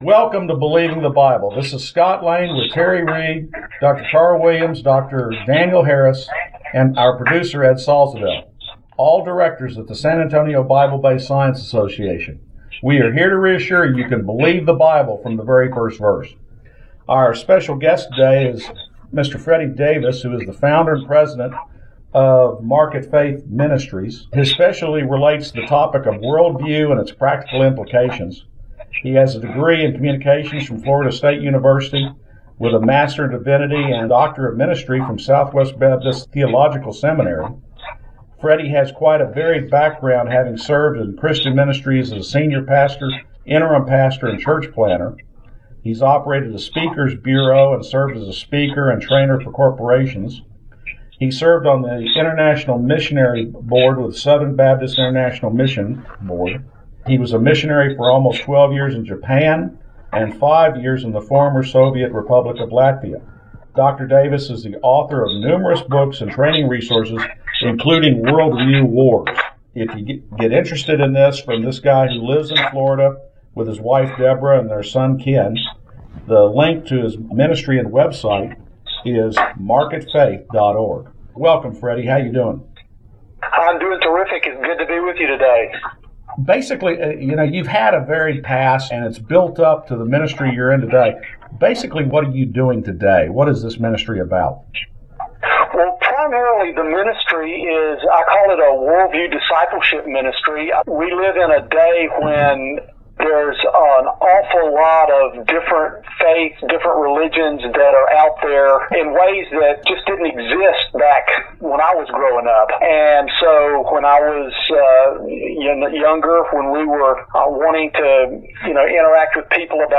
Believing the Bible Interview #1 - MarketFaith Ministries